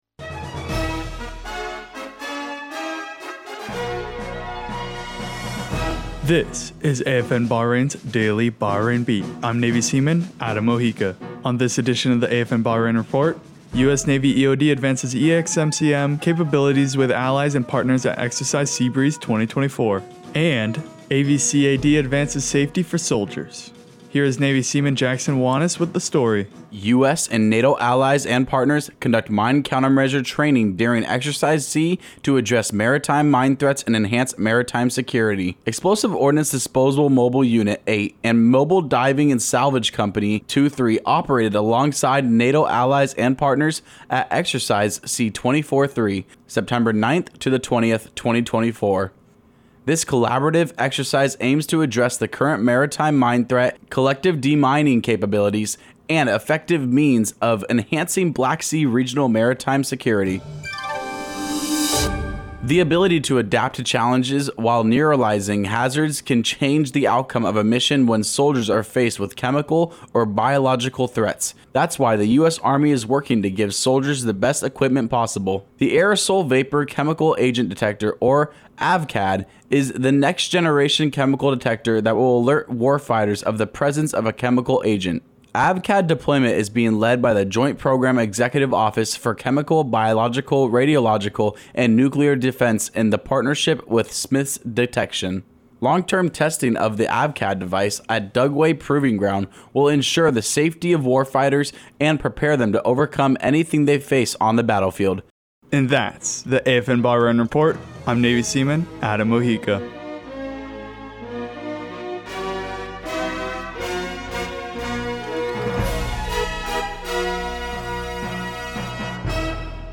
Two-minute newscast covering U.S. Navy Eod Advances Exmcm Capabilities With Allies And Partners At Exercise Sea Breeze 2024 and AVCAD Advances Safety For Soldiers.